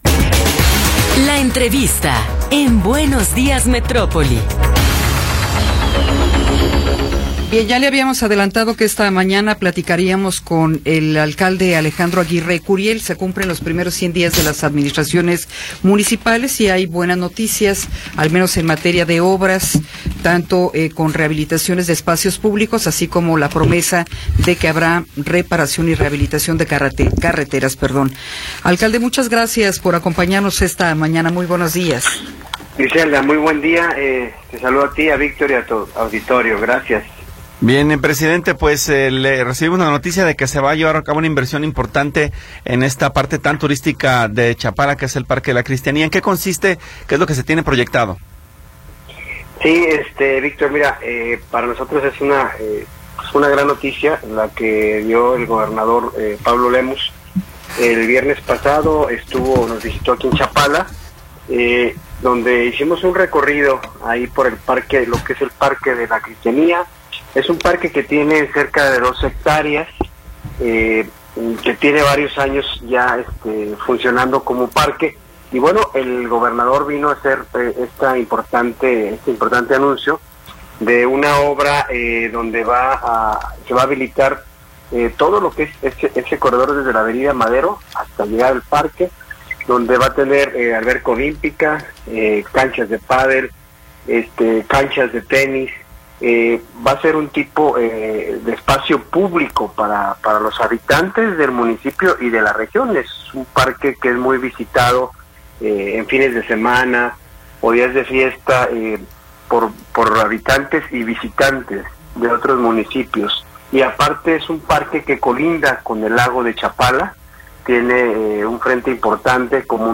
Entrevista con Alejandro Aguirre Curiel